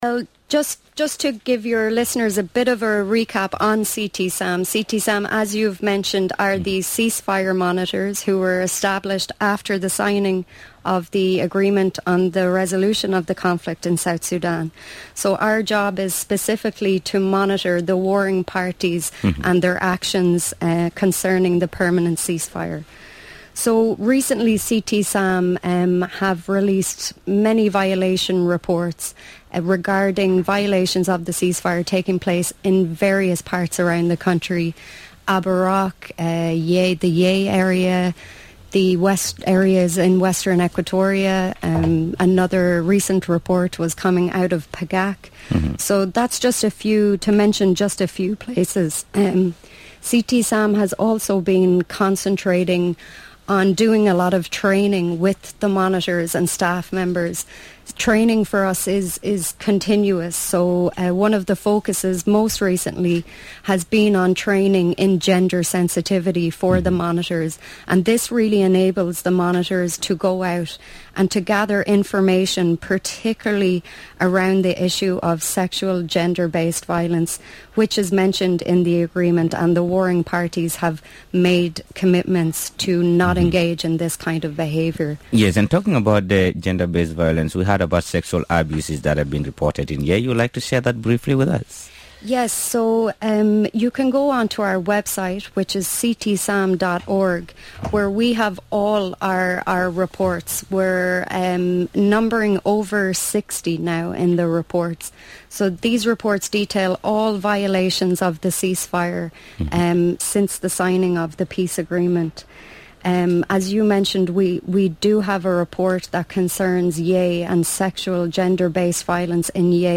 Radio Miraya